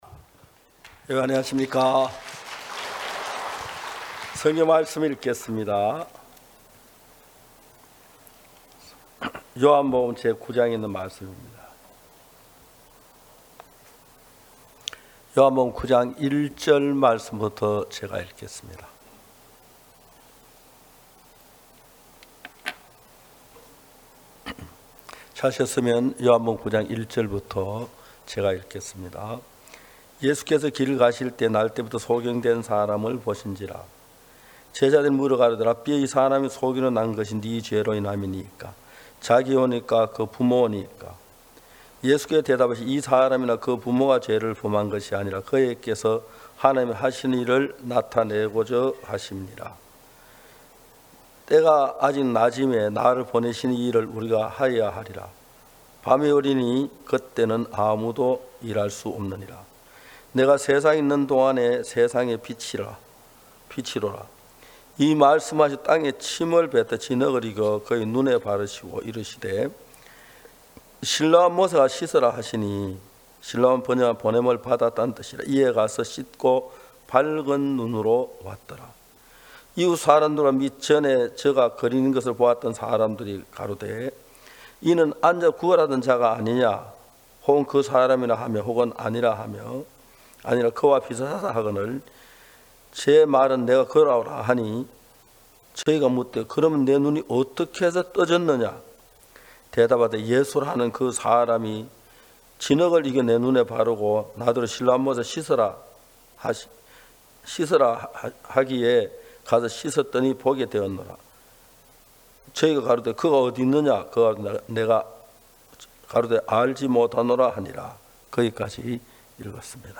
성도들이 모두 교회에 모여 말씀을 듣는 주일 예배의 설교는, 한 주간 우리 마음을 채웠던 생각을 내려두고 하나님의 말씀으로 가득 채우는 시간입니다.